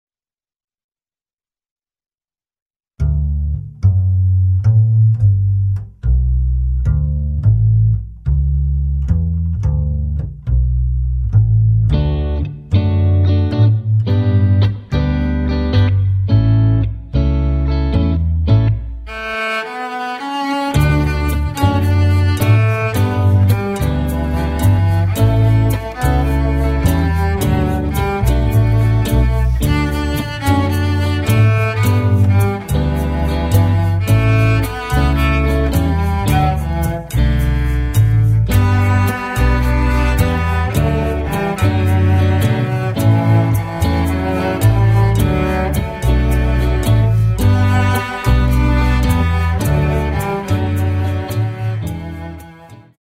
• für 1-2 Celli